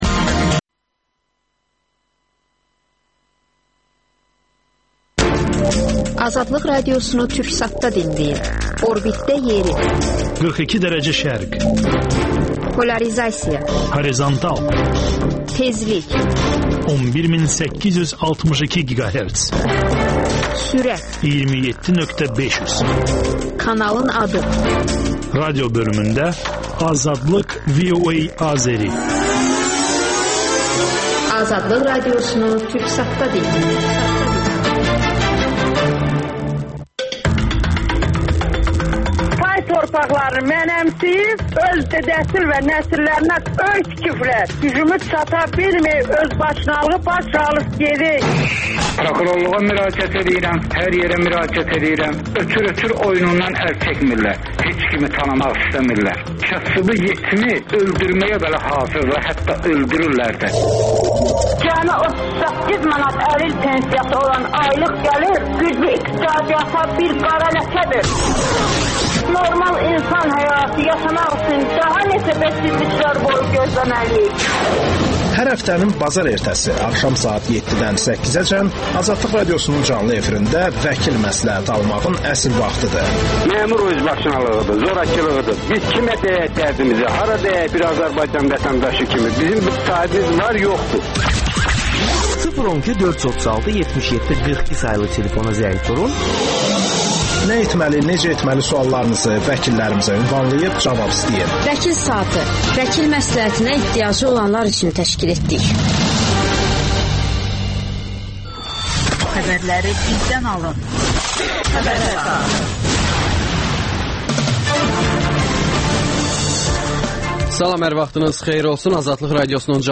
AzadlıqRadiosunun müxbirləri ölkə və dünyadakı bu və başqa olaylardan canlı efirdə söz açırlar. Günün sualı: Azərbaycana hansı birlik daha önəmlidir: Avrasiya İttifaqı, yoxsa Avropa İttifaqı?